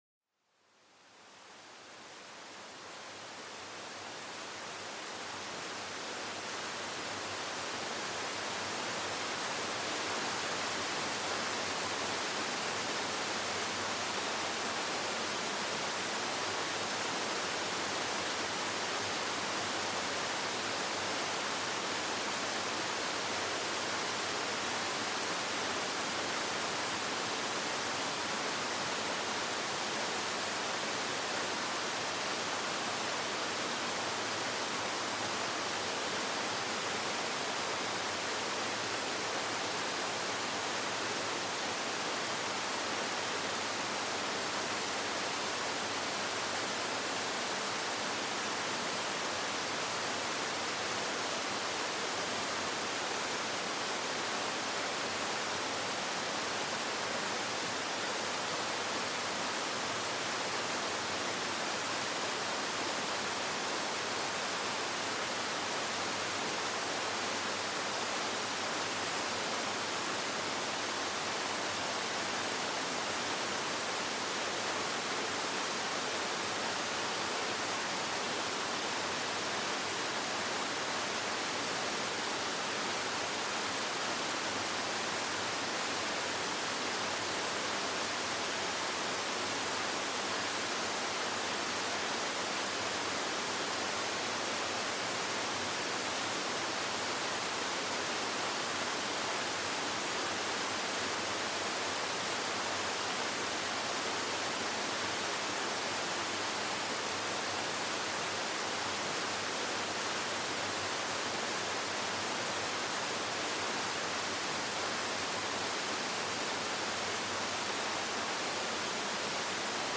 Sumérgete en el corazón de una poderosa cascada, donde el estruendo del agua se convierte en una sinfonía relajante. Déjate envolver por el sonido envolvente y constante que despierta los sentidos a la belleza cruda de la naturaleza.